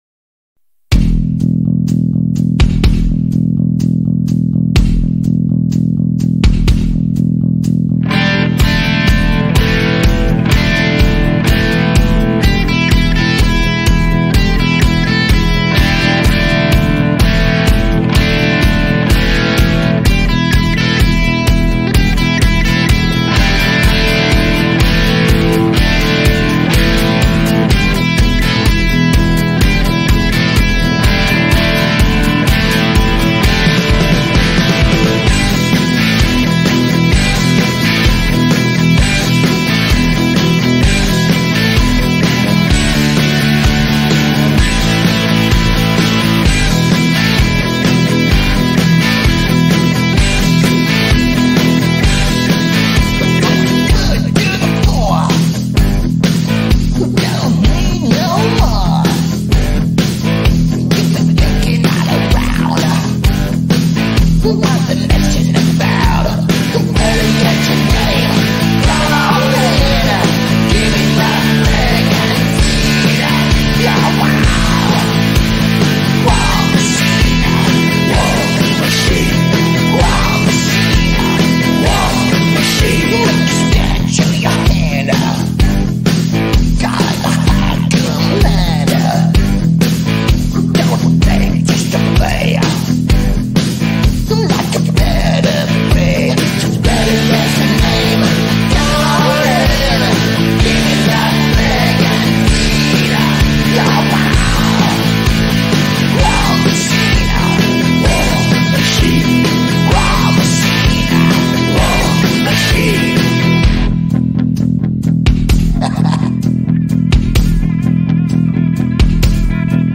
Great Rock n Roll cool video thanks \G/